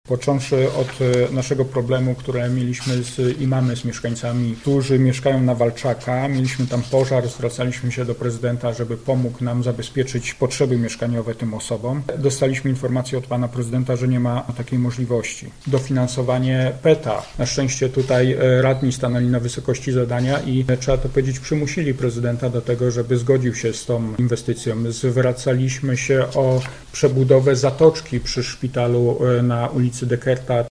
Do tej pory zwracaliśmy się do miasta z wieloma prośbami o pomoc, nie otrzymywaliśmy jednak pozytywnych odpowiedzi – wymieniał na konferencji prasowej radny Koalicji Obywatelskiej Robert Surowiec, który jest także wiceprezesem szpitala.